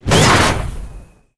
launch_pri_firev2a.wav